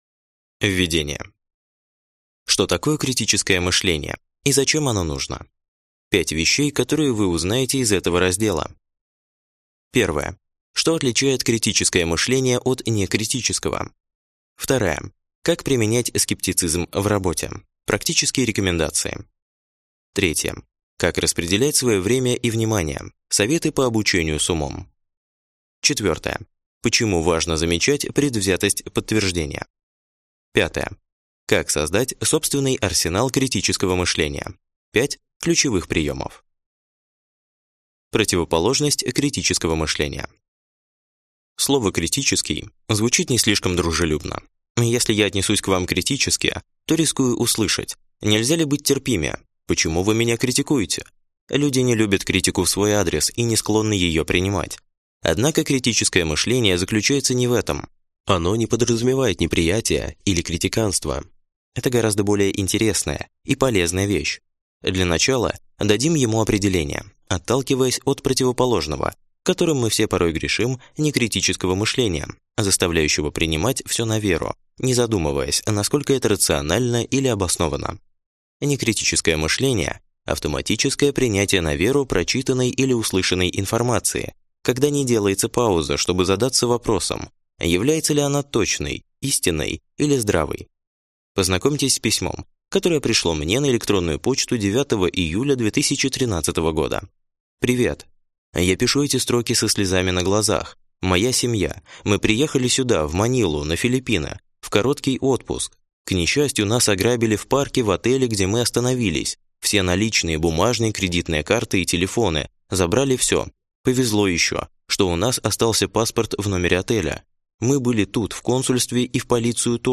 Аудиокнига Критическое мышление. Анализируй, сомневайся, формируй свое мнение | Библиотека аудиокниг